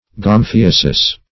Search Result for " gomphiasis" : The Collaborative International Dictionary of English v.0.48: Gomphiasis \Gom*phi"a*sis\, n. [NL., fr. Gr.